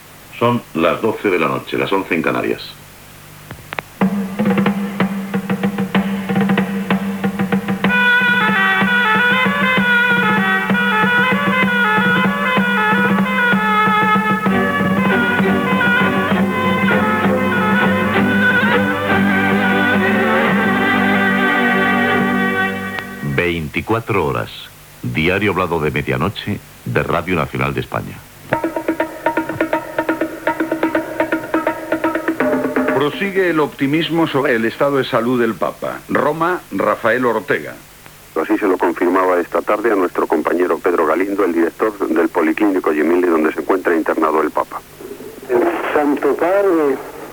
Hora, careta de l'informatiu, connexió amb Roma per saber l'estat del Papa Joan Pau II, ingressat l Policlínic Gemelli (després que li extirpessin la bala que li va disparar Mehmet Ali Ağca a la Plaça de Sant Pere).
Informatiu